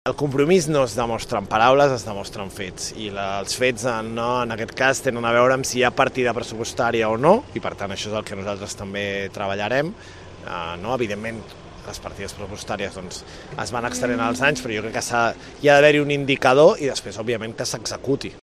Cid, acompanyat del regidor Sebastián Tejada, de Calella en Comú Podem, han compartit la inquietud pels retards en el calendari d’execució de les obres d’ampliació de l’hospital, que ara en situa l’entrada en funcionament per a l’any 2030.